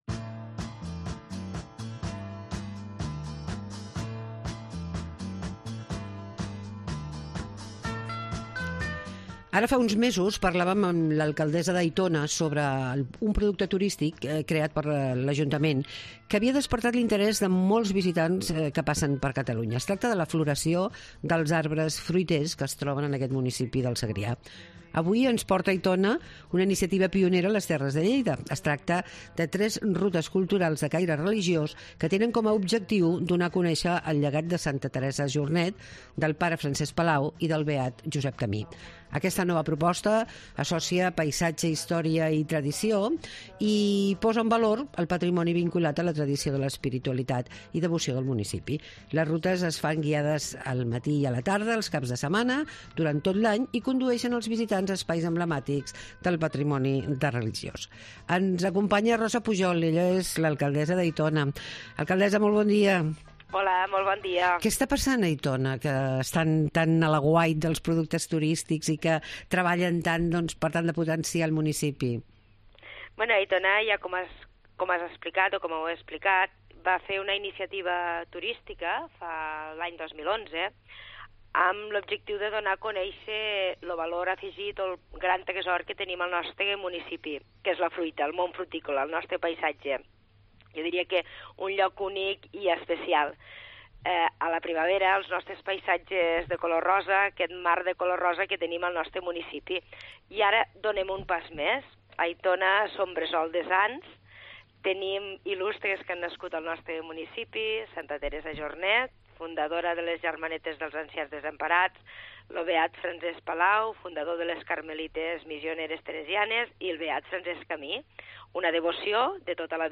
Parlem amb Rosa Pujol, alcaldessa de Aitona